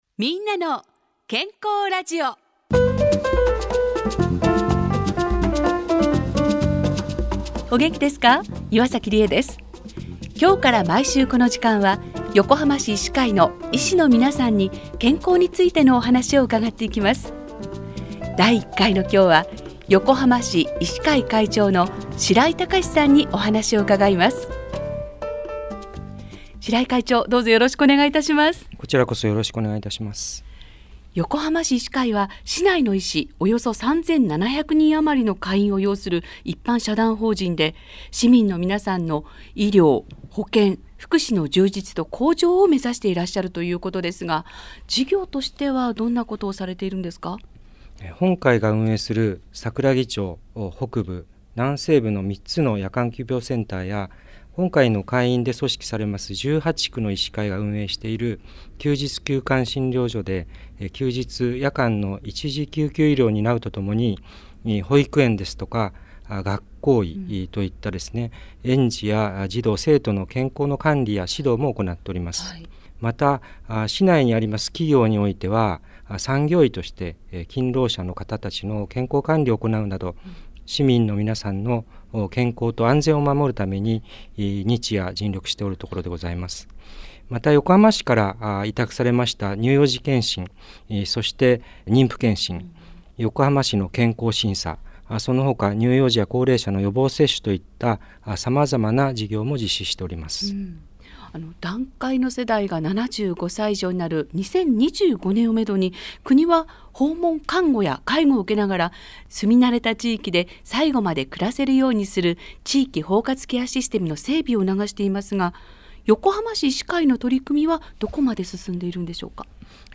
「みんなの健康ラジオ」9月8日(木)スタート！番組は毎週木曜日11時05分からAM1422kHz ラジオ日本でオンエア